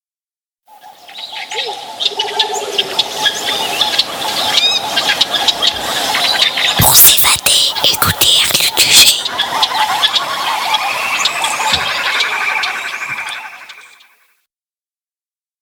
Style : Documentaire Sonore